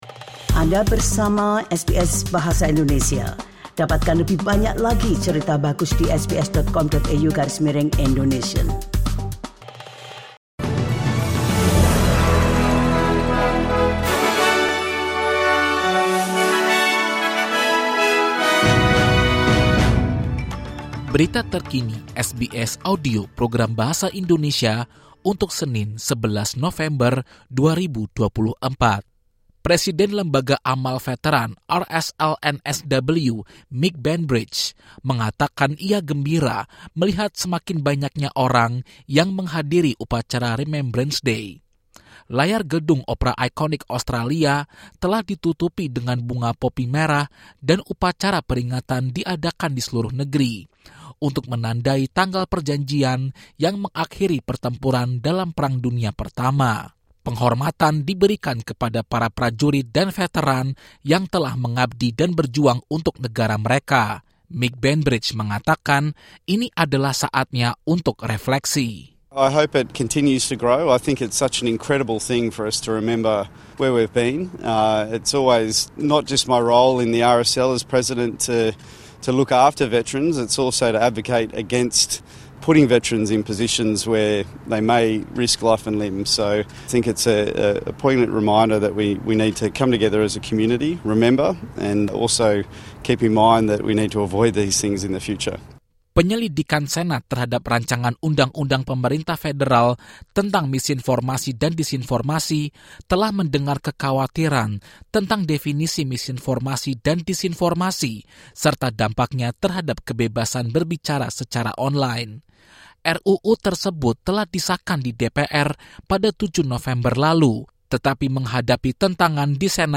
Berita Terkini SBS Audio Program Bahasa Indonesia - 12 November 2024